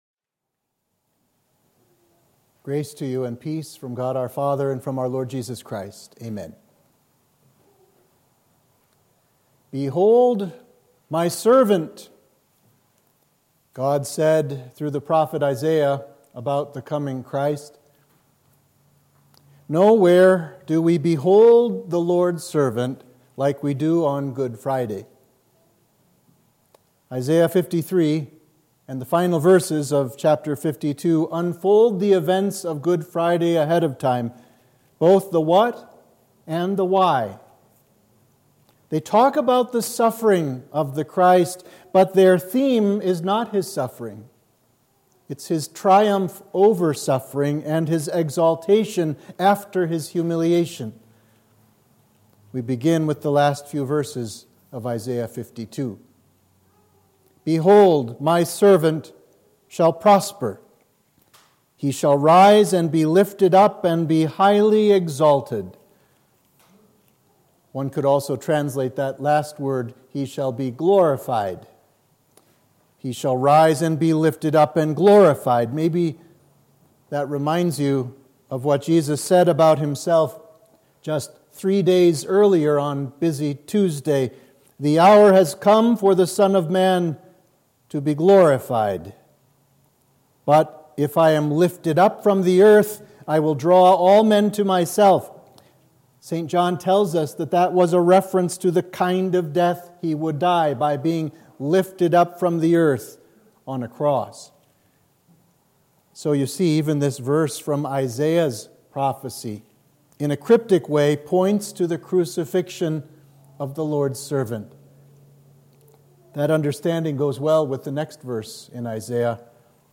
Sermon for Good Friday